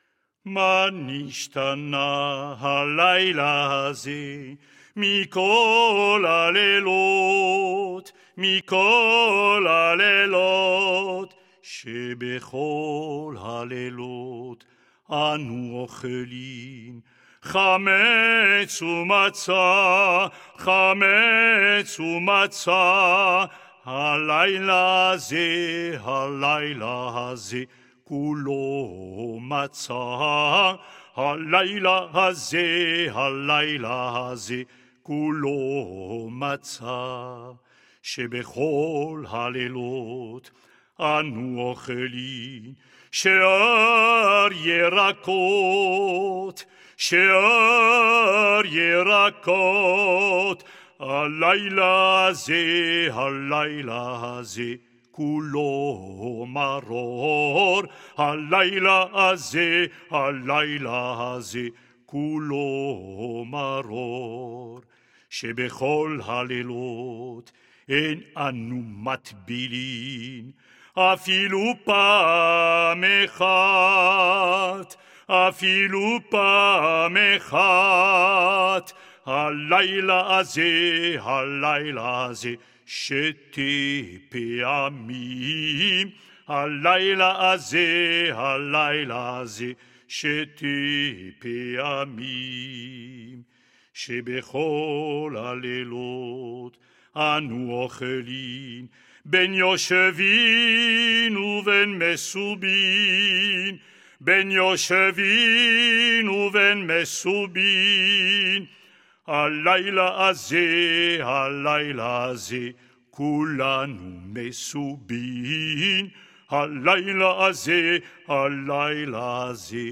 « Ma nichtana » – ou Les Quatre Questions – est chanté pendant le séder de Pessa’h, traditionnellement par les enfants.